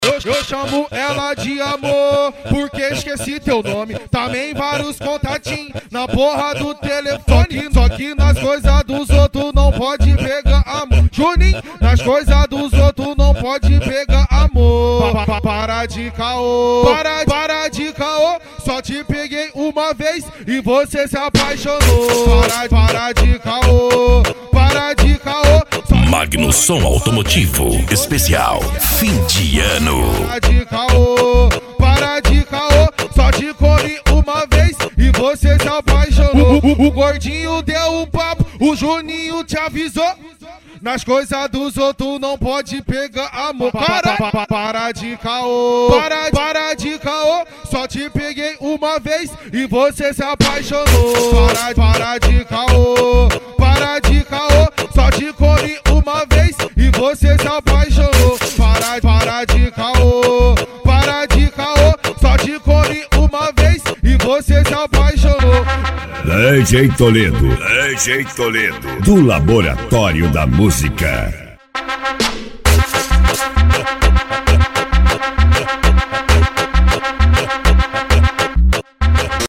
Funk
Sertanejo Universitario